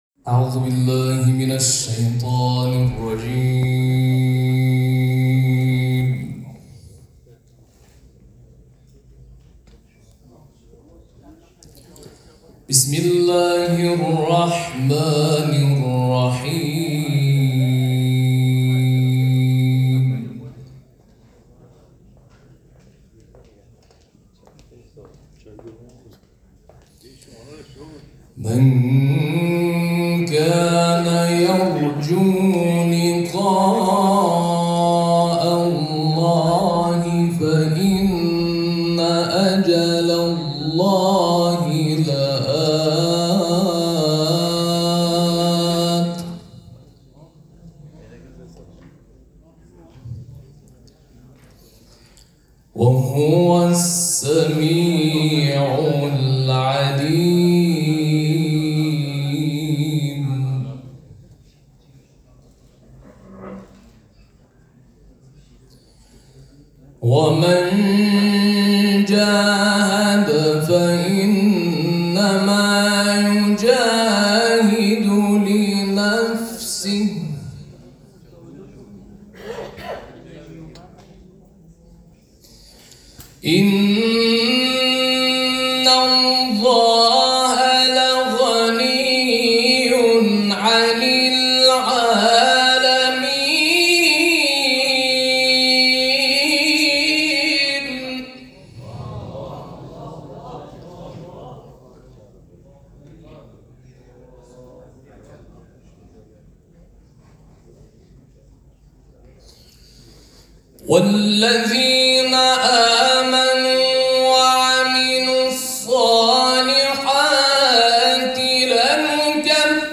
تلاوت
در چهل و پنجمین دوره مسابقات سراسری قرآن